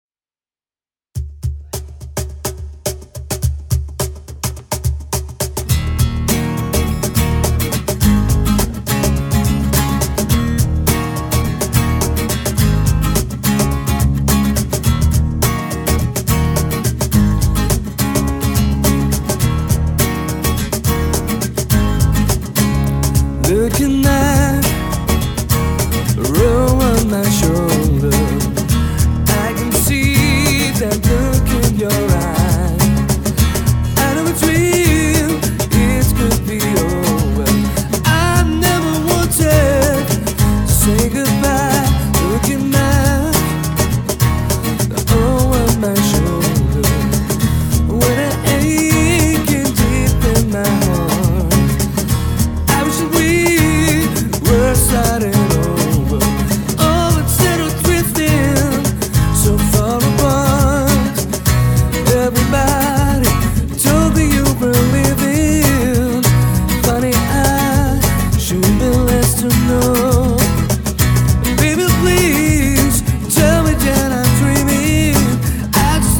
cajon & drums